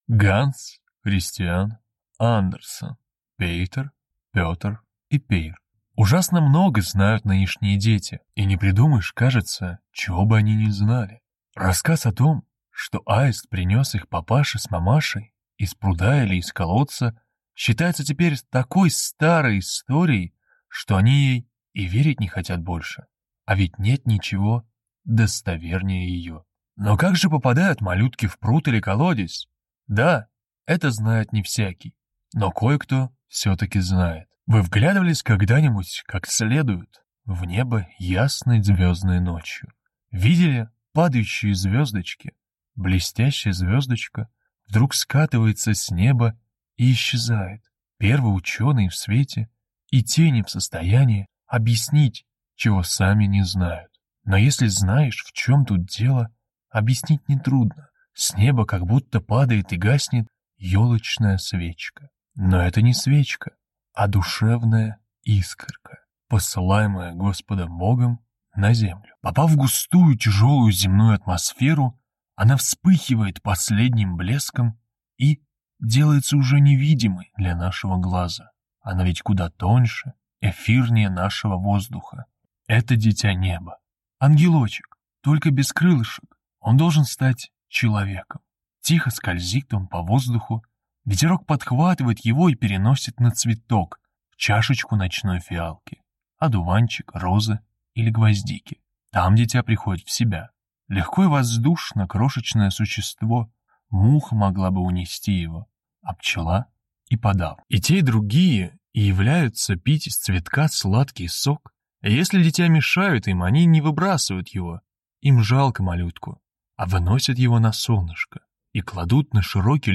Аудиокнига Пейтер, Пётр и Пейр | Библиотека аудиокниг